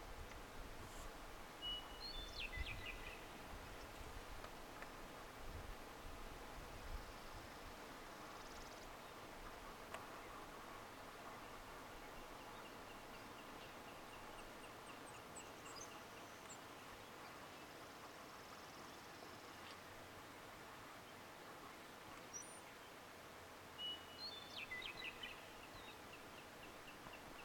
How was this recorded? Western Meadowlark, Yellowstone, May 2014